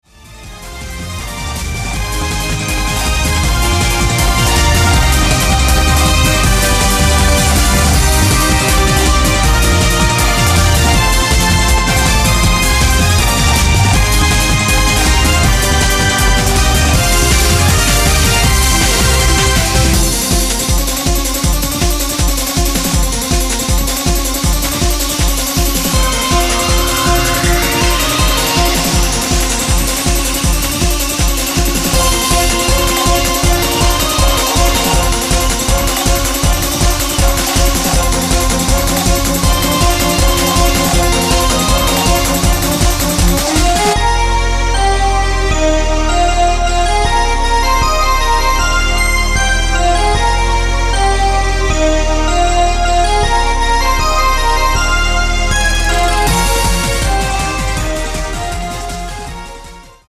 Track 1-11 ... リマスタリングver